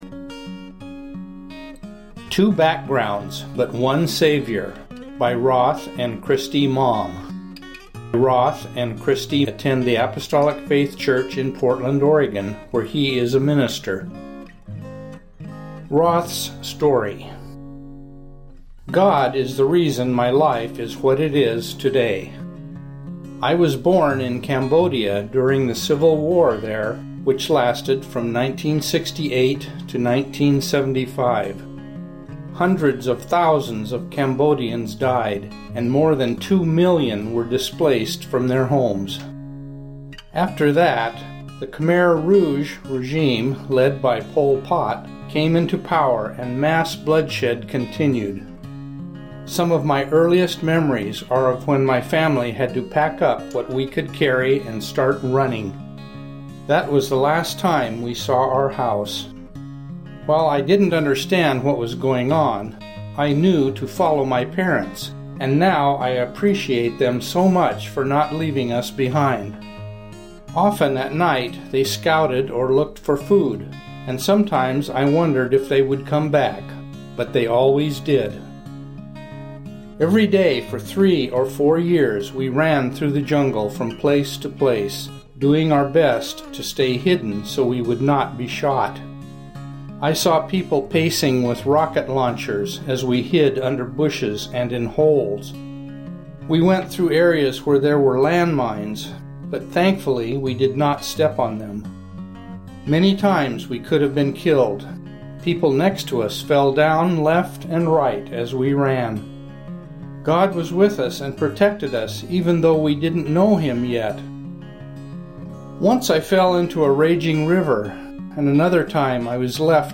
Witness